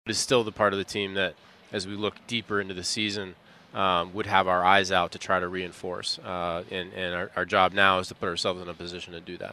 On The Ben Cherington Show on WCCS yesterday, the Pirates’ general manager said he likes the team’s pitching, which he expects to get even better.
Cherington says he is always looking for bats.